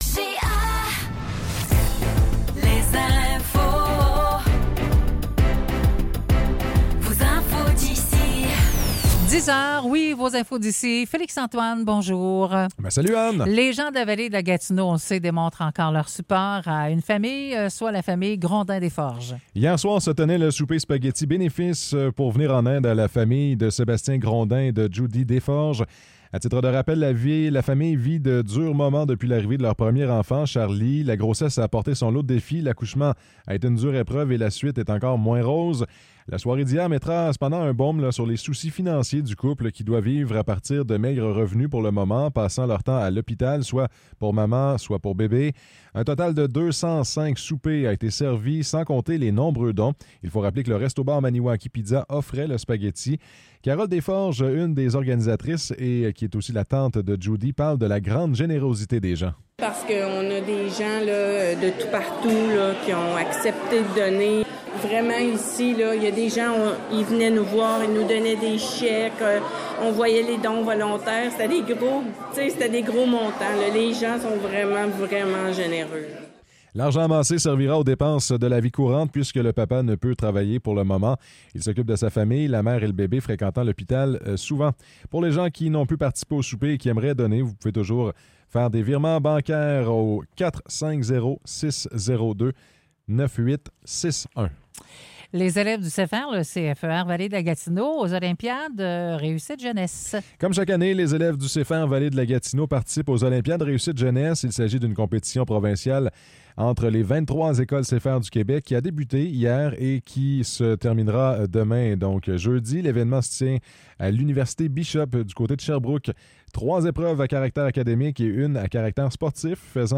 Nouvelles locales - 29 mai 2024 - 10 h